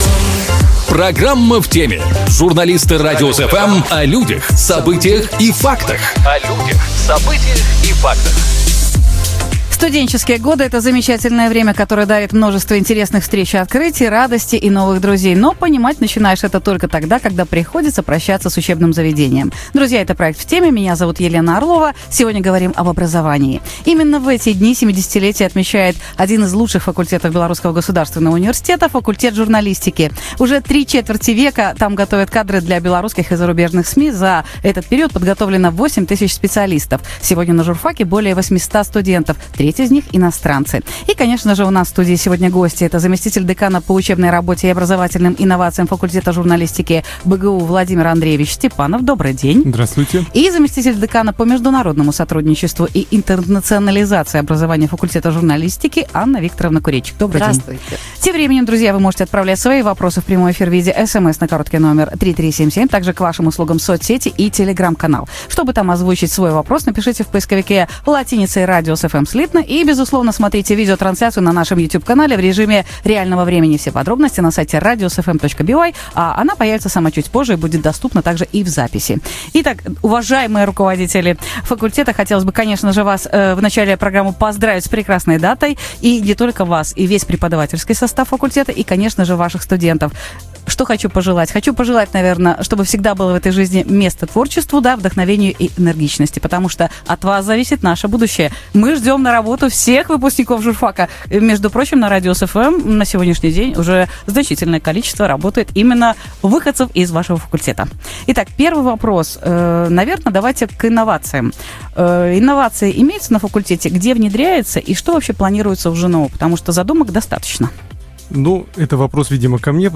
У нас в студии